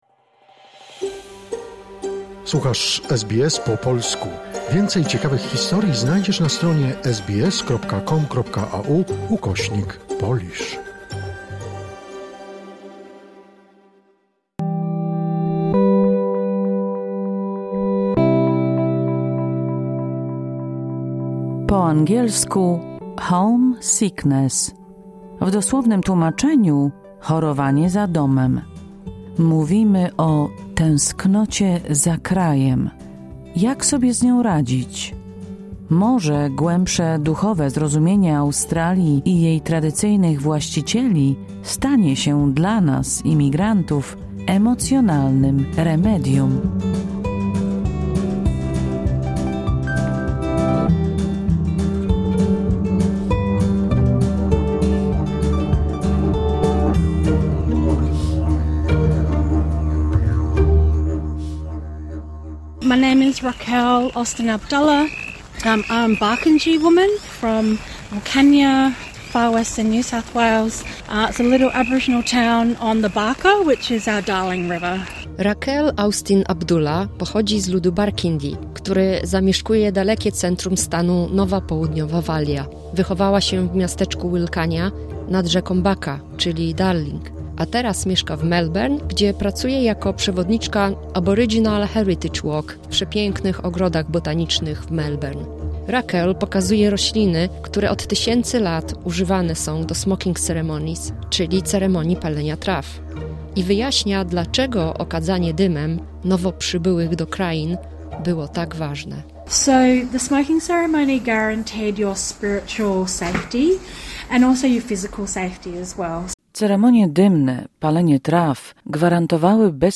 Rozmowy o tęsknocie za krajem z polskimi emerytami, którzy od wielu lat żyją w Australii, mogą wiele nauczyć młodsze pokolenia. Jak radzili sobie w trudnych początkach?